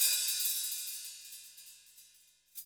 HIHAT OP 9.wav